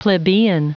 Prononciation du mot plebeian en anglais (fichier audio)
Prononciation du mot : plebeian